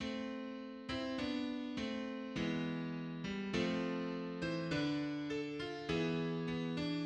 In the above example, a chromatic false relation occurs in two adjacent voices sounding at the same time (shown in red). The tenor voice sings G while the bass sings G momentarily beneath it, producing the clash of an augmented unison.